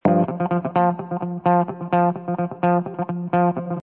descargar sonido mp3 guitarra